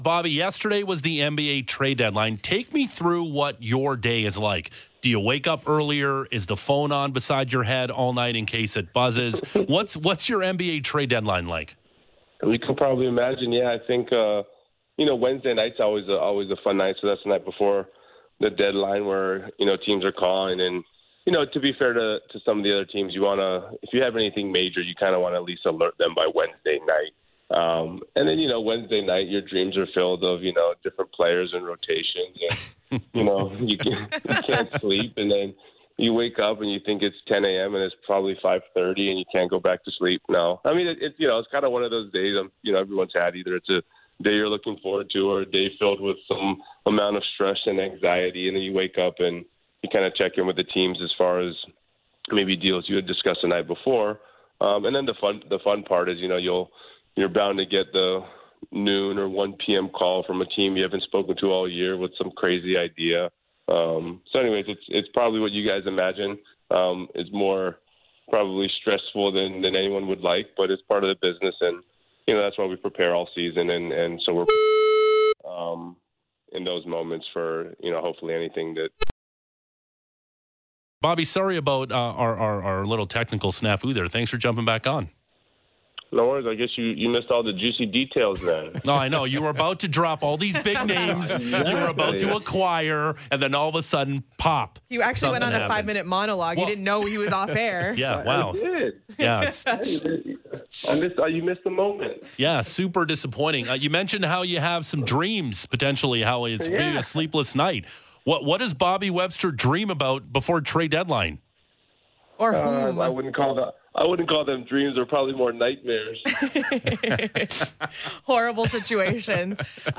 Listen to Raptors GM Bobby Webster’s full interview on Sportsnet 590’s Lead Off via the audio player embedded in this post.